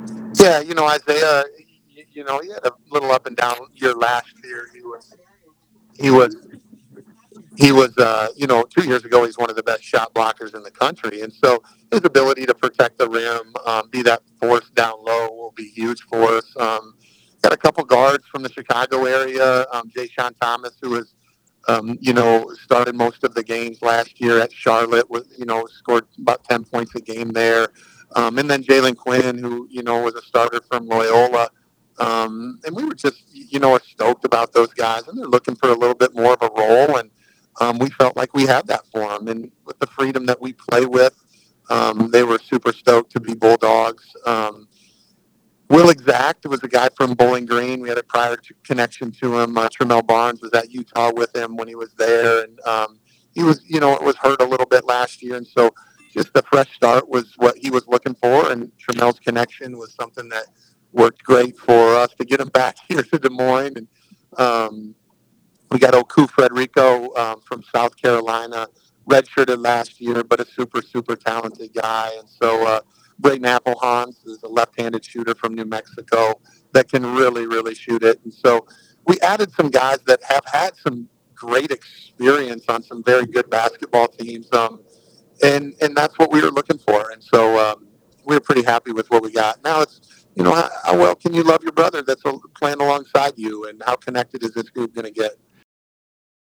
During our interview he repeatedly talks about other people.